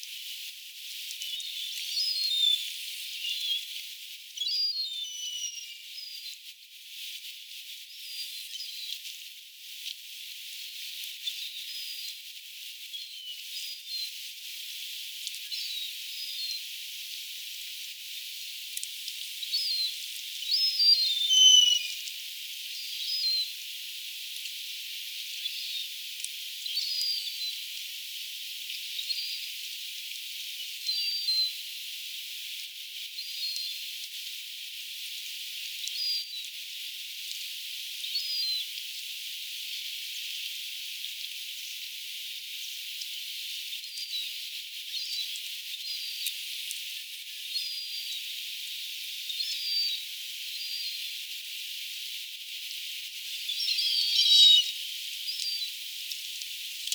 Niiden kovaäänistä ääntelyä kuului,
nuorten lokkien ääntelyä kalasatamassa
nuorten_lokkien_aantelya_kalasatamassa.mp3